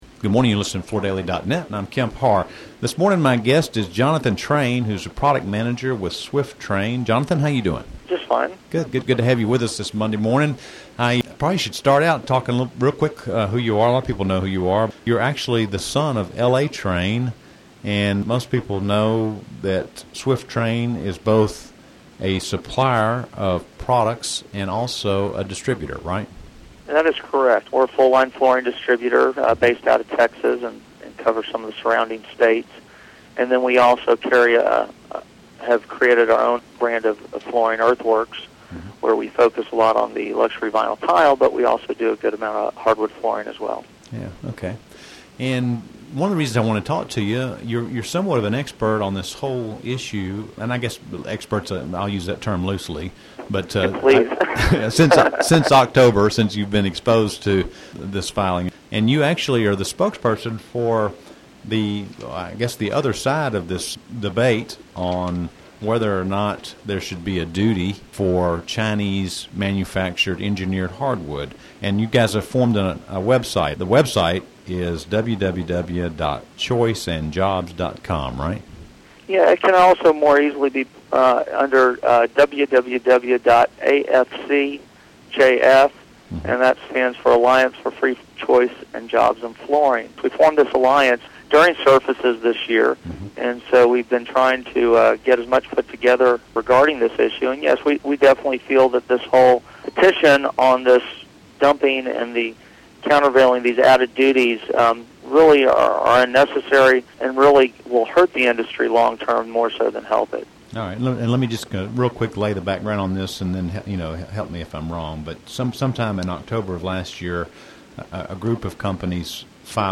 Listen to the interview to hear the background on this debate and where it stands today.